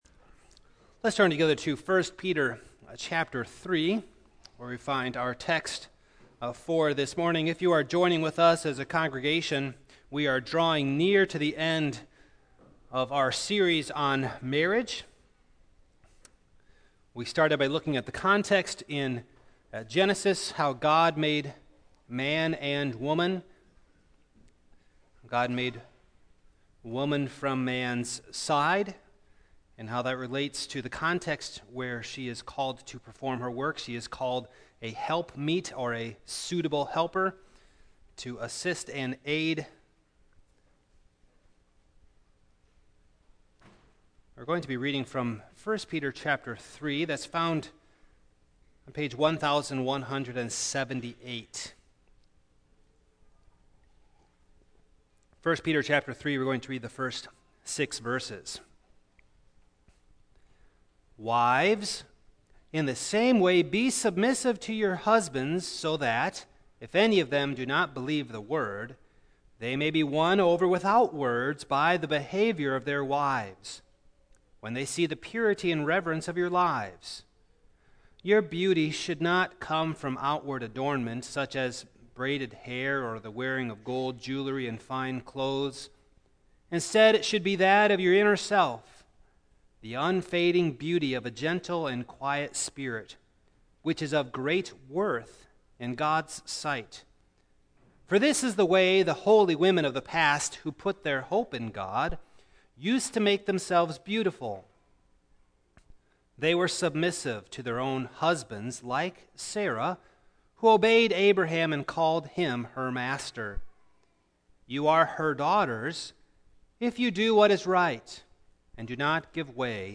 2017 Woman in Marriage Preacher